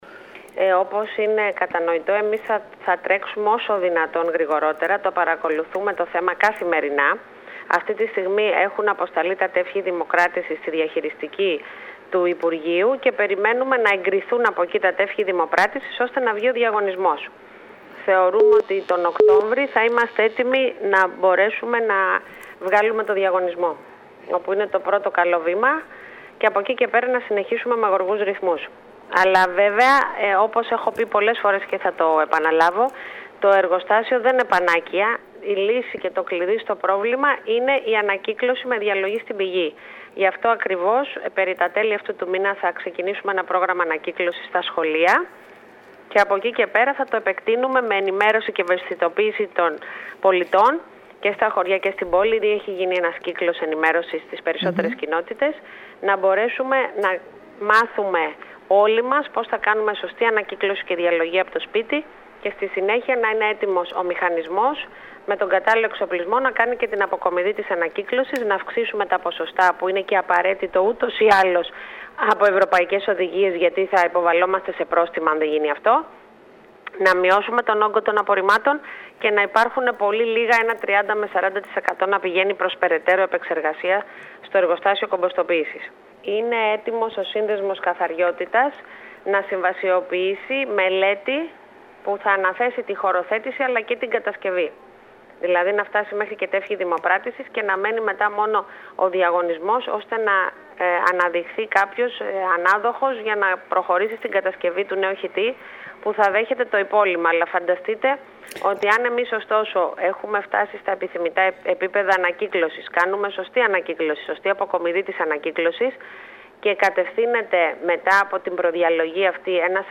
Μιλώντας στην ΕΡΤ Κέρκυρας η δήμαρχος κεντρικής κέρκυρας και διαποντίων νησιών Μερόπη Υδραίου αναφέρθηκε στο θέμα του εργοστασίου ολοκληρωμένης διαχείρισης των απορριμμάτων υπογραμμίζοντας ότι  έχουν αποσταλεί  τα τεύχη δημοπράτησης στη διαχειριστική του υπουργείου περιβάλλοντος και έως τον Οκτώβρη θα έχει γίνει ο διαγωνισμός.  Παράλληλα τέλος του μήνα, όπως είπε, ο δήμος  ξεκινάει πρόγραμμα ενημέρωσης για την ανακύκλωση στα σχολεία.